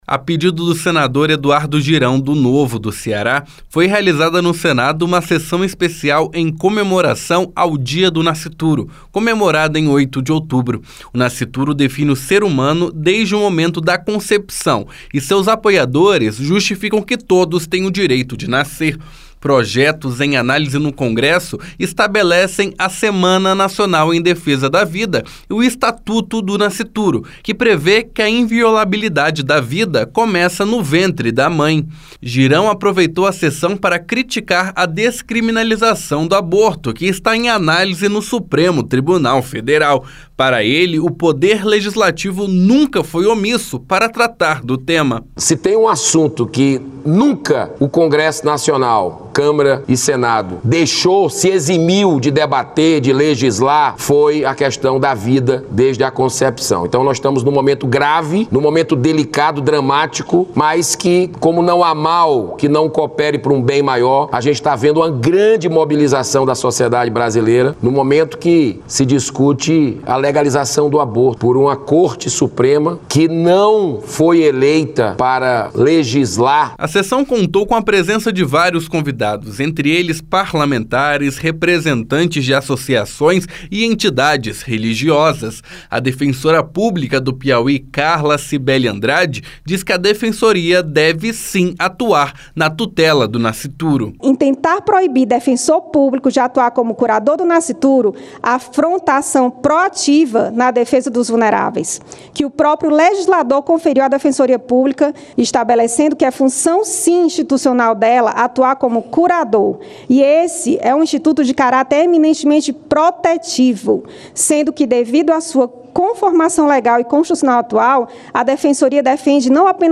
O Senado Federal fez nesta quinta-feira (5) sessão especial para celebrar o Dia do Nascituro. O requerente da sessão, senador Eduardo Girão (Novo-CE), aproveitou a solenidade para criticar a descriminalização do aborto, que está em análise no Supremo Tribunal Federal (STF). Os senadores Damares Alves (Republicanos-DF) e Magno Malta (PL-ES) defenderam a vida desde a concepção.